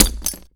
grenade_hit_concrete_01.WAV